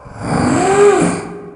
zombie_attack_1.ogg